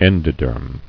[en·do·derm]